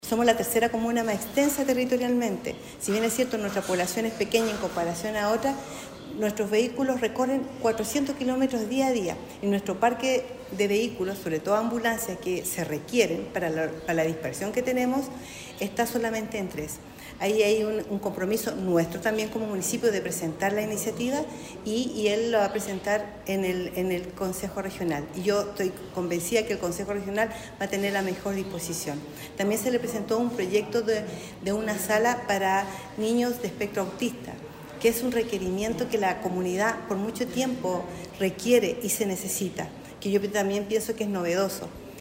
Finalmente, la alcaldesa de La Higuera, Uberlinda Aquea, reforzó que
ALCALDESA-DE-LA-HIGUERA-UBERLINDA-AQUEA.mp3